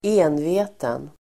Ladda ner uttalet
Uttal: [²'e:nve:ten]
enveten.mp3